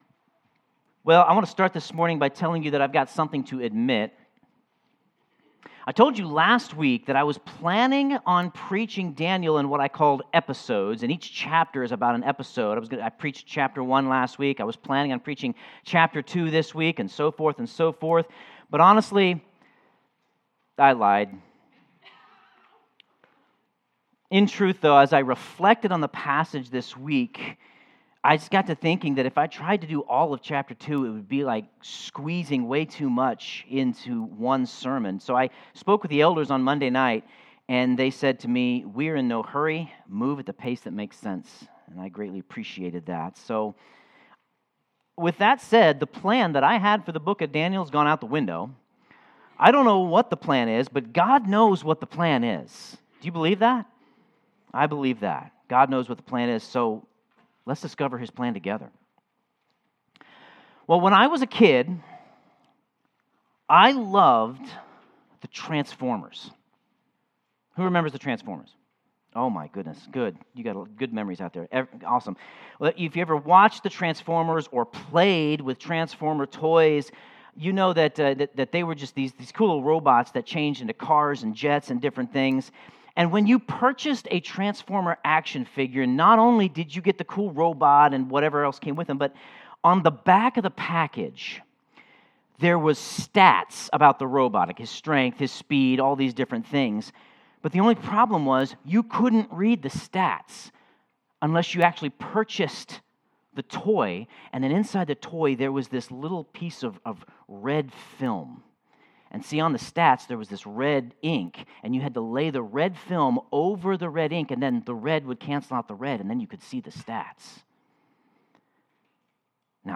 Sermon Notes We need to look to God, not to man, to understand God.